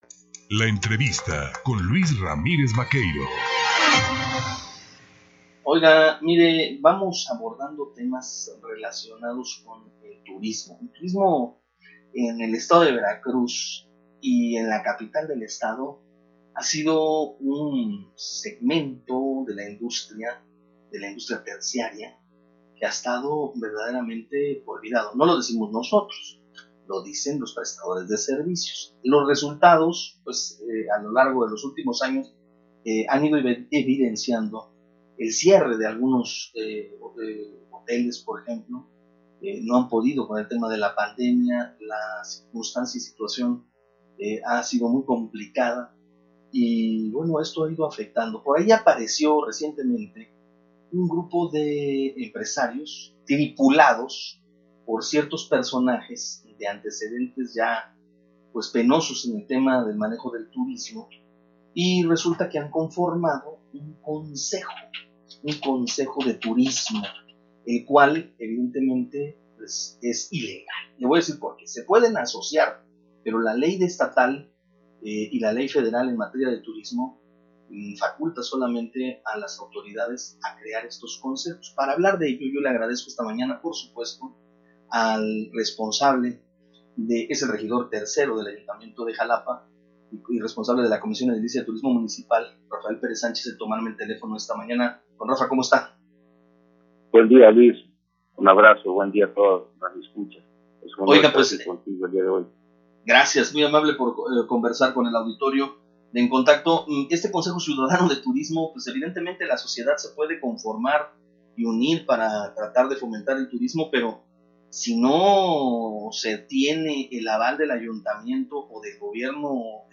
Xalapa. El titular de la Comisión de Turismo de Xalapa, Rafael Pérez Sánchez, señaló en entrevista para la primera emisión de “En Contacto” que el supuesto Consejo Ciudadano de Turismo encabezado por algunos empresarios de la ciudad, no cuenta con el aval del Ayuntamiento.